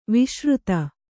♪ viśruta